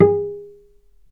vc_pz-G#4-mf.AIF